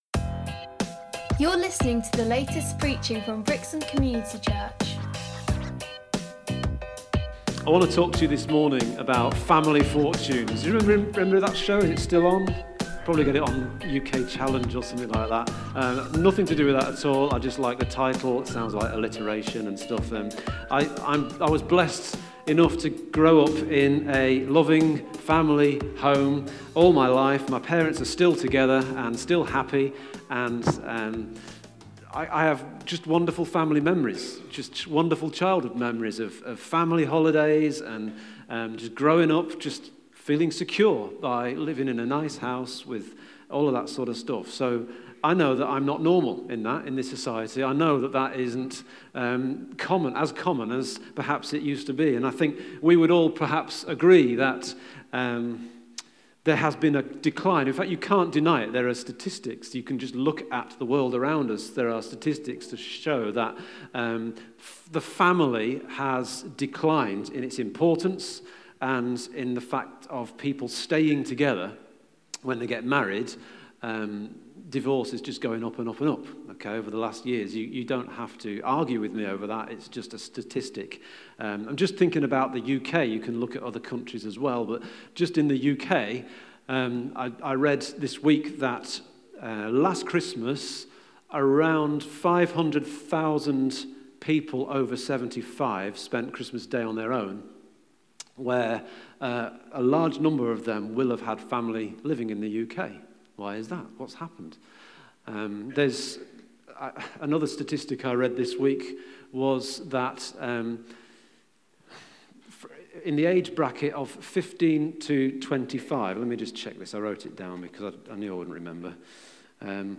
Hear the latest preaching from Brixham Community Church.